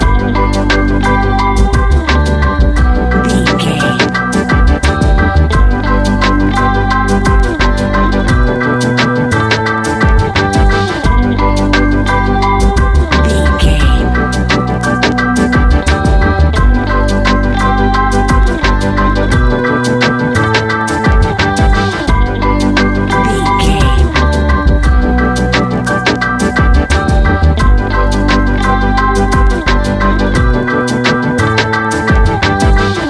Ionian/Major
Lounge
sparse
chilled electronica
ambient